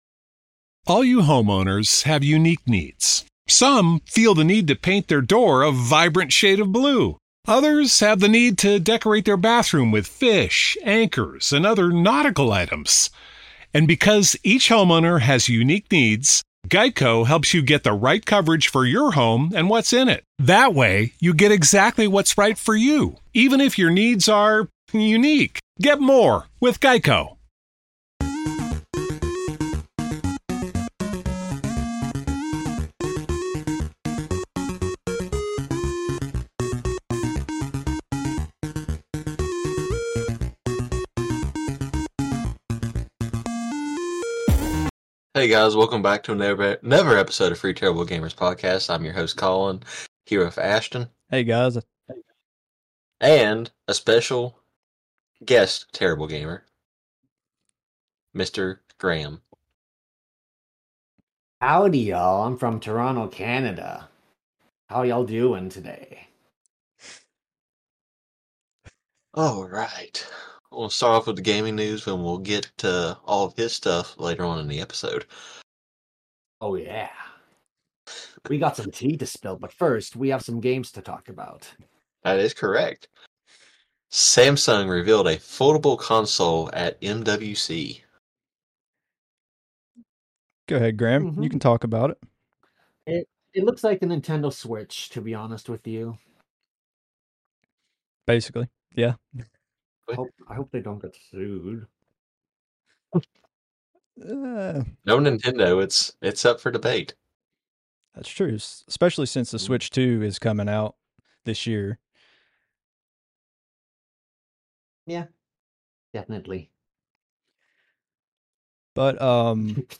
Just a few guys discussing hot gaming topics, news, and rumors across a variety of consoles.